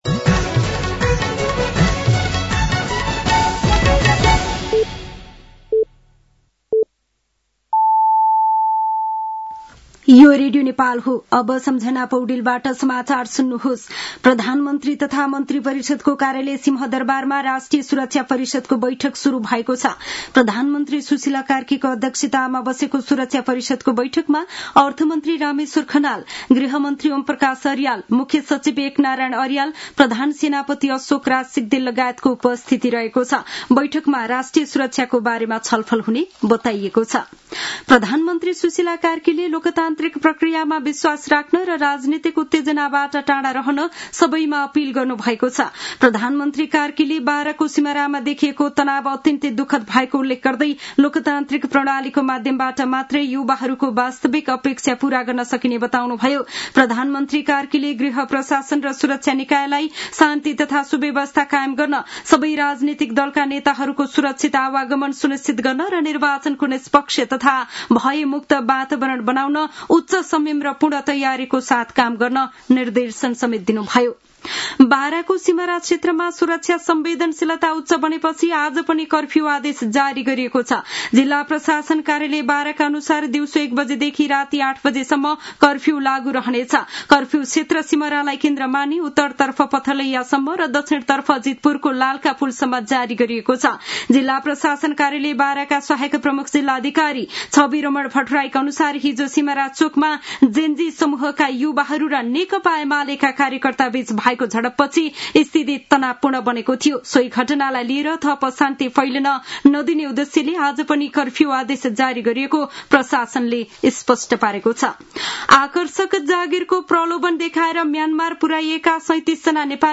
साँझ ५ बजेको नेपाली समाचार : ४ मंसिर , २०८२
5-pm-news-8-4.mp3